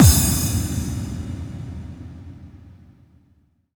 VEC3 FX Reverbkicks 04.wav